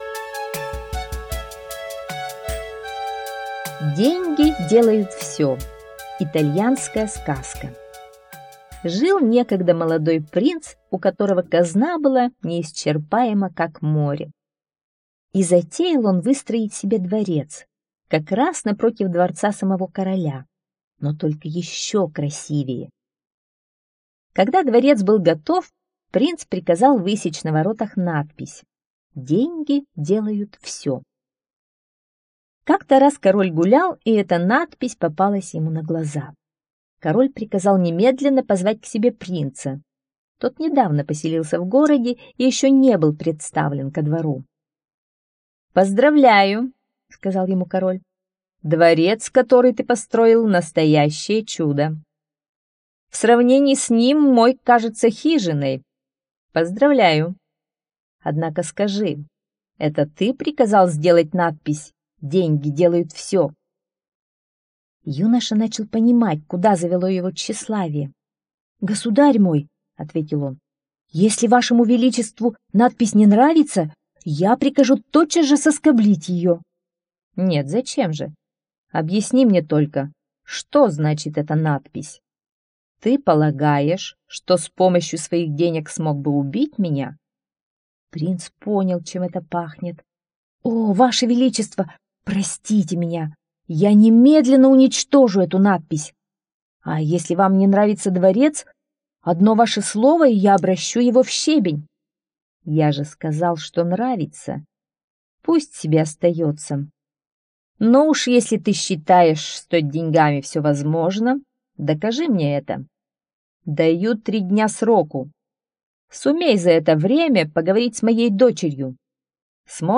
Деньги делают всё - итальянская аудиосказка. Сказка про богатого молодого принца, который попал в сложную ситуацию.